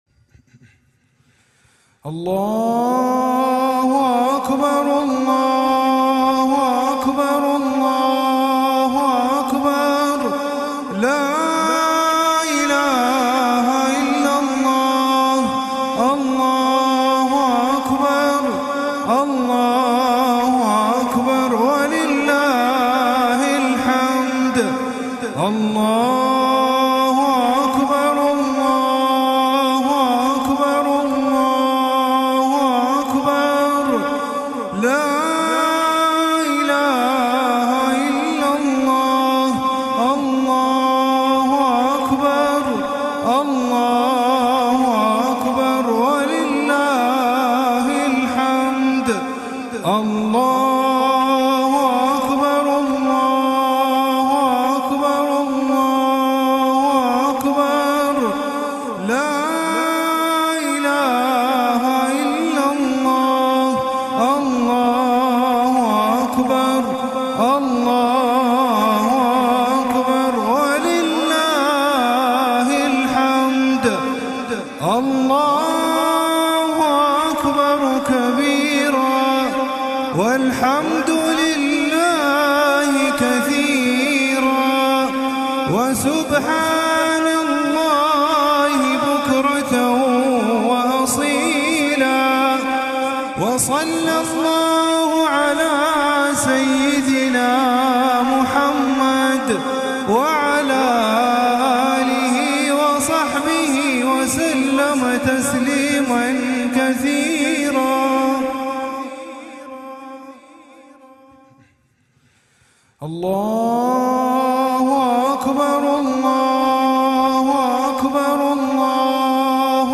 رابط تحميل تكبيرات عيد الفطر mp3 بأجمل الأصوات إذ يبحث الكثير من المسلمين حول العالم عن تكبيرات العيد بصيغة mp3، حيث يكبّر المسلم في صلاته تعظيمًا لله تعالى وشكرًا له على نعمه التي لا تعد ولا تحصى.
يمكنكم الاستمتاع بسماع تكبيرات عيد الفطر بصيغة mp3 عبر المقطع الصوتي التالي:
تحميل-تكبيرات-عيد-الفطر-بصيغة-mp3.mp3